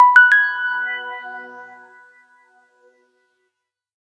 infocomputernotification.ogg